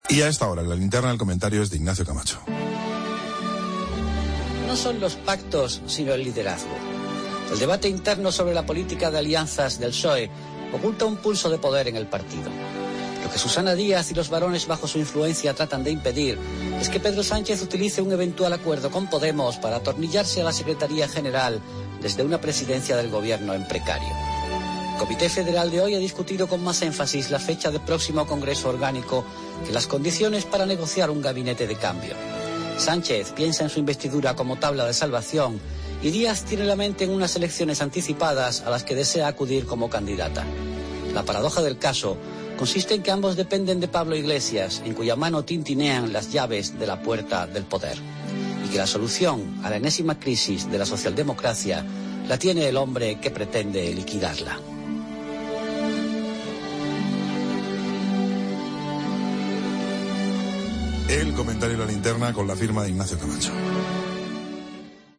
El comentario de Ignacio Camacho está dedicado a las negociaciones de pactos que se llevan a cabo estos días a la espera de que se forme el nuevo Gobierno de España.